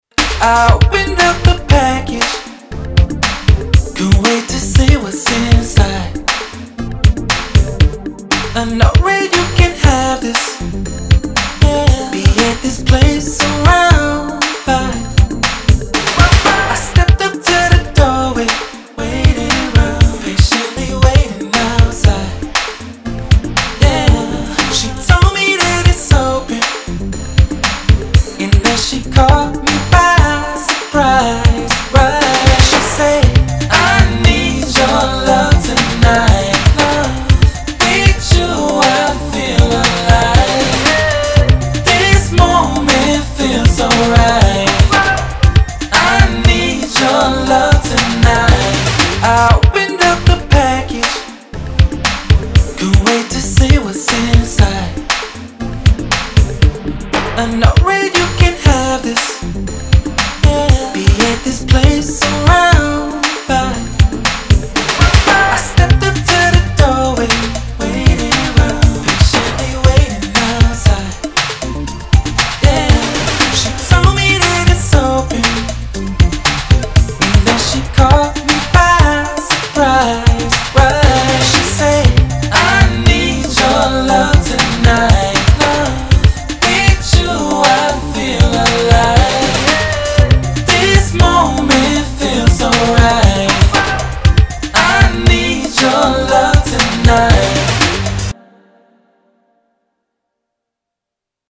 80s, R&B
G minor